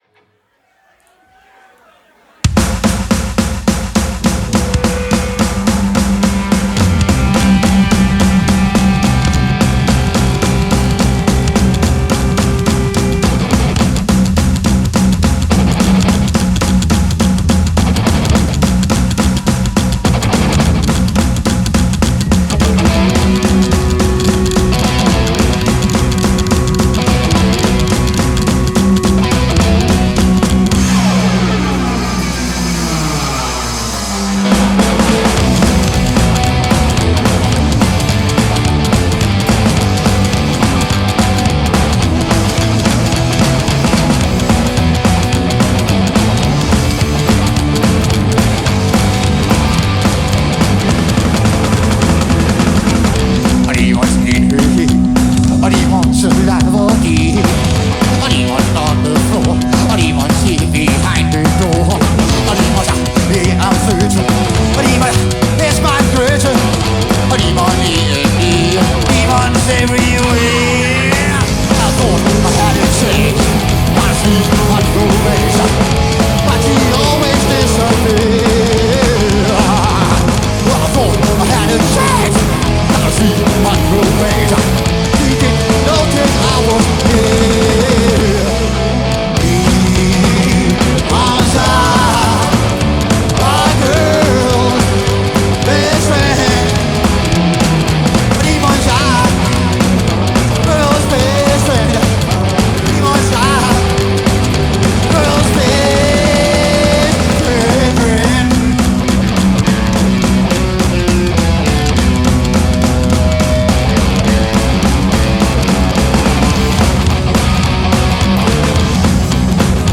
Recorded live at the Observatory Theater in Santa Ana, CA.